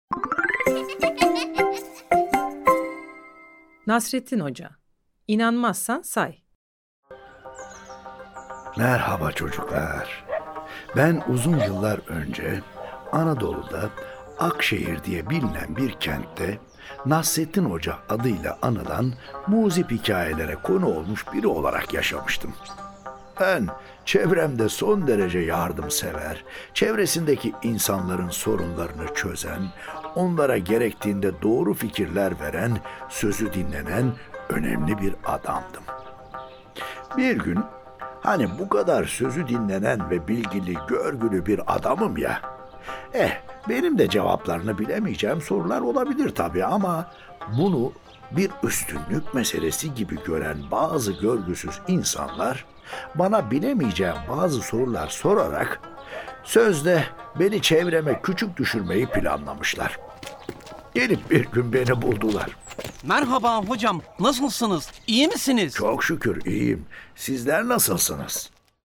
Nasreddin Hoca: İnanmazsan Say Tiyatrosu